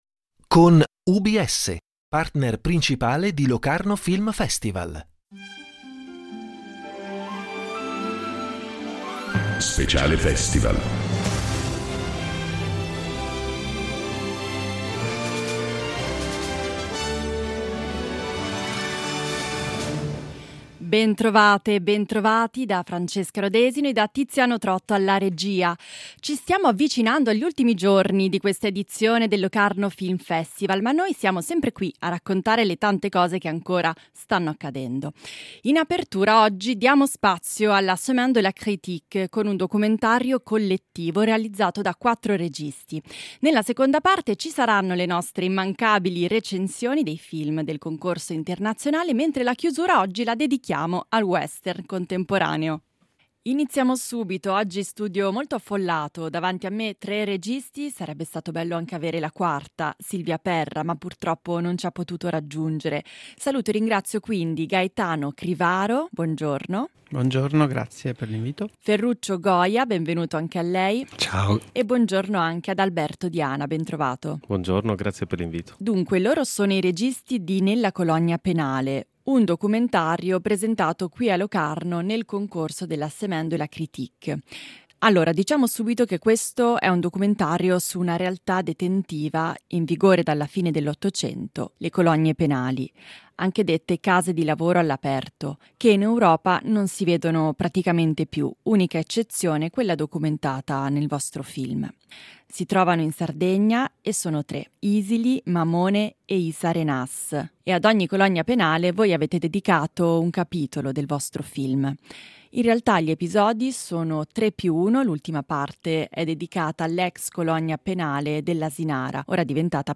In diretta dal Locarno Film Festival